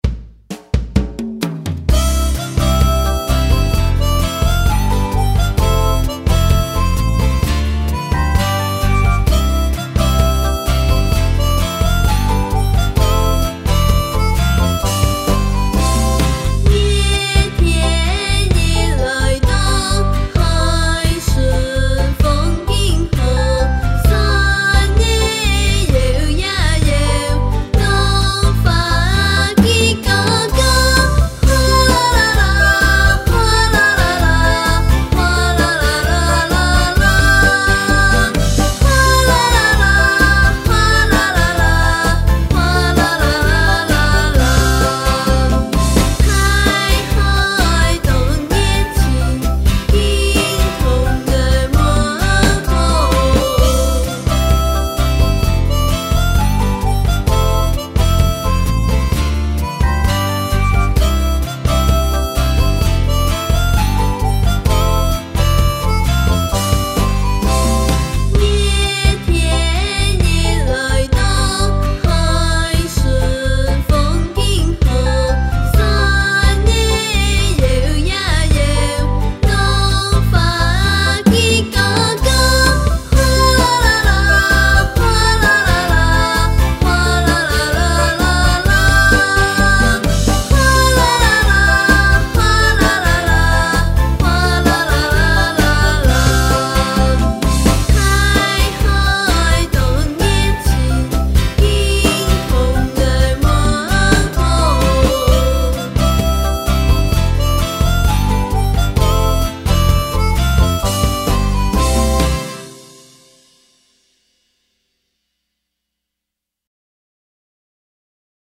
看海(完整演唱版) | 新北市客家文化典藏資料庫